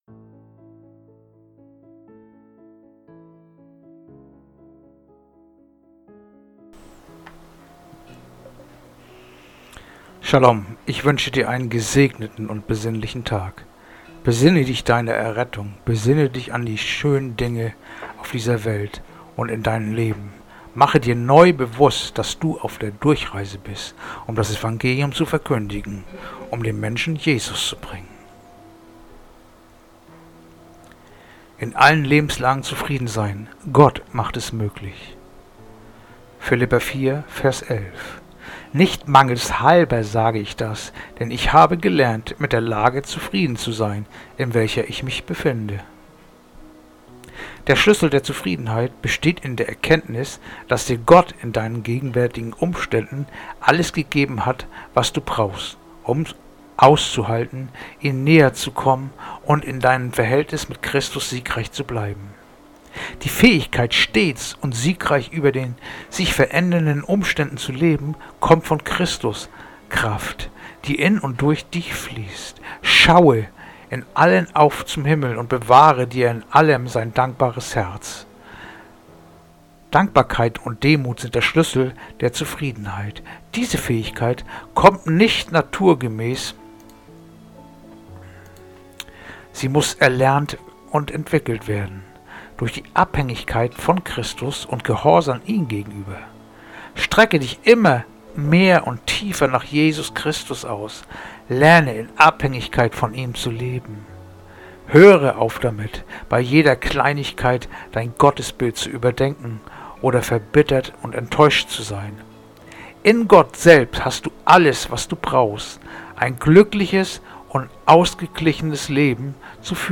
Andacht-vom-04-Februar-Philipper-4-11
Andacht-vom-04-Februar-Philipper-4-11.mp3